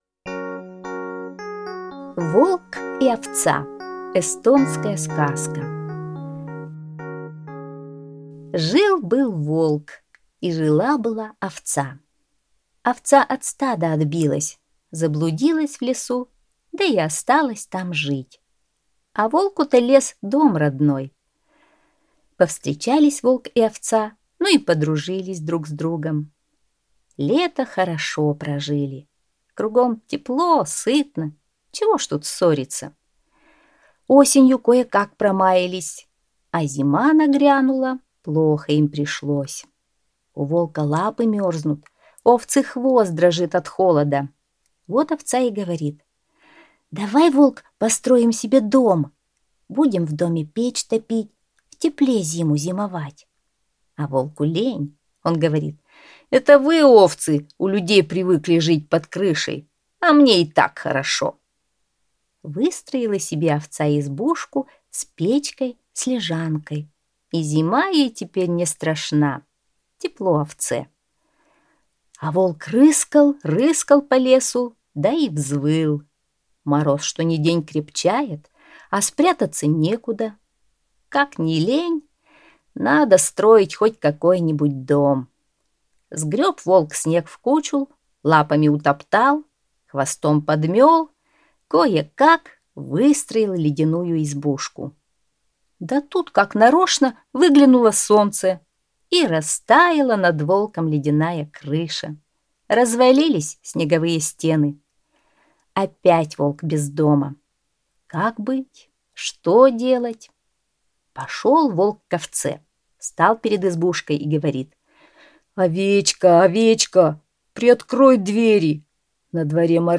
Эстонская аудиосказка